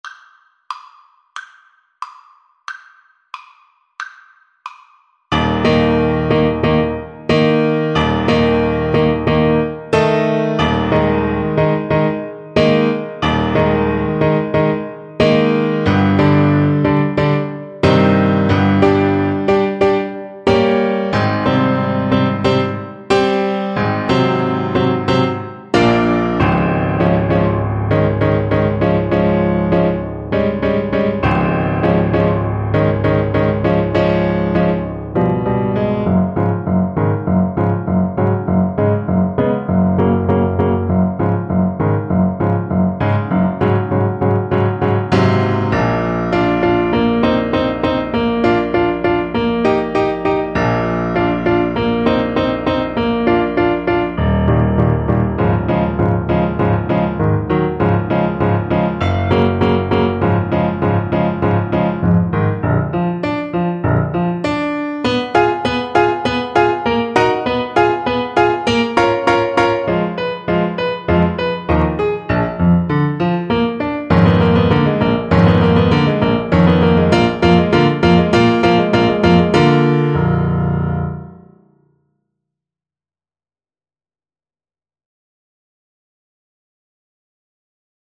Alto Saxophone version
Alto Saxophone
2/4 (View more 2/4 Music)
Allegro =132 (View more music marked Allegro)
Ab4-Db6
Classical (View more Classical Saxophone Music)